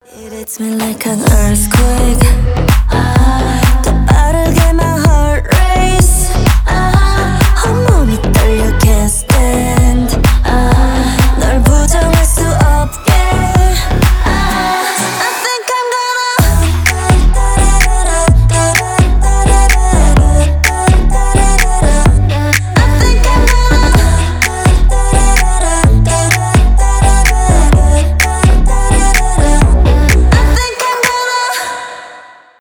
k-pop , поп